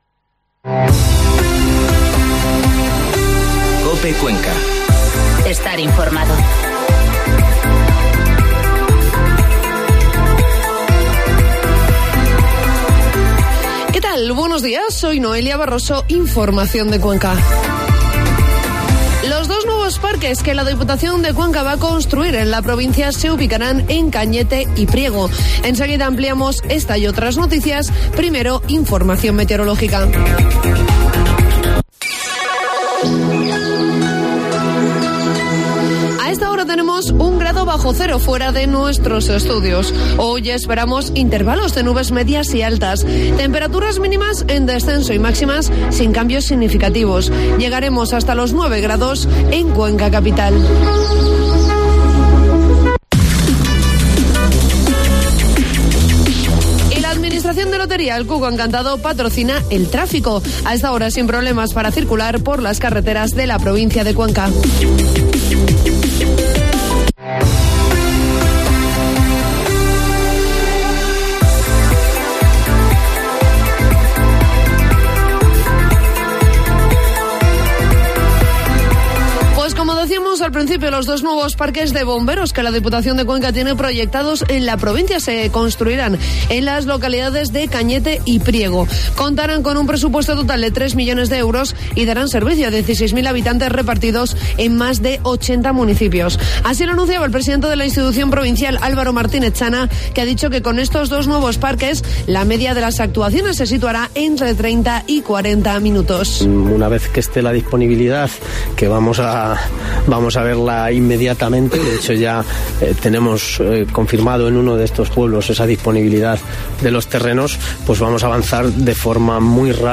Informativo matinal COPE Cuenca 19 de noviembre